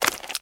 STEPS Swamp, Walk 27.wav